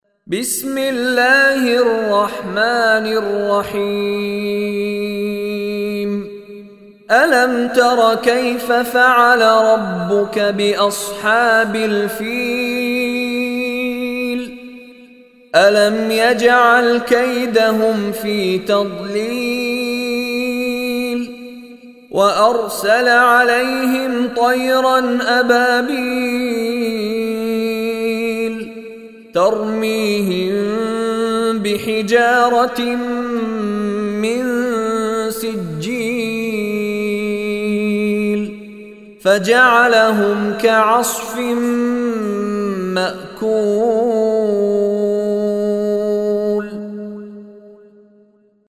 Surah Fil Recitation by Mishary Rashid Alafasy
Surah Al Fil is 105 chapter of holy Quran. Listen online and download mp3 tilawat / recitation of Surah Al Fil in the beautiful voice of Sheikh Mishary Rashid Alafasy.